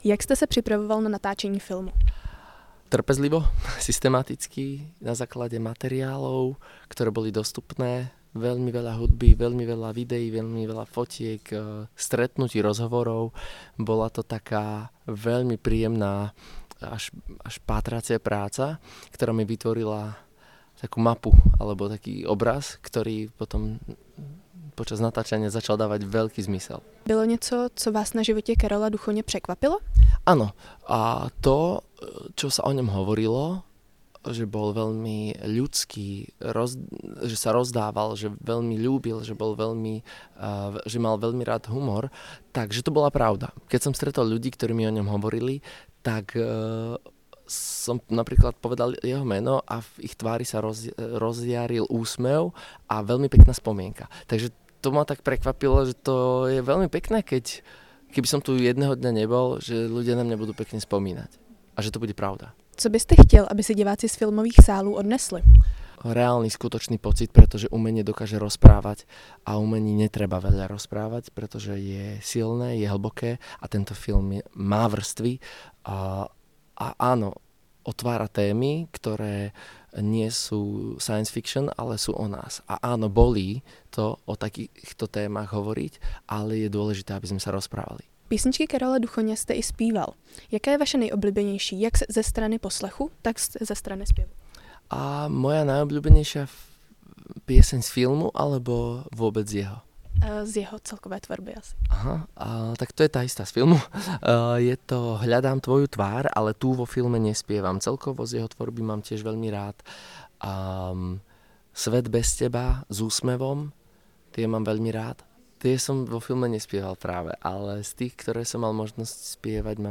Rozhovor s hercem